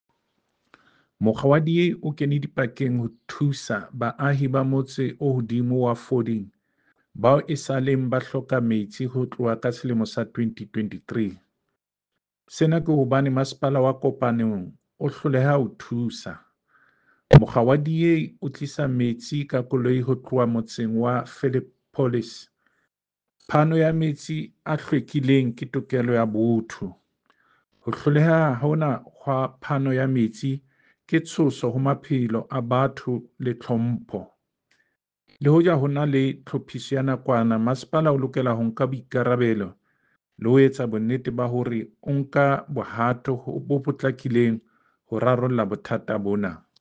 Sesotho soundbite by David Masoeu MPL.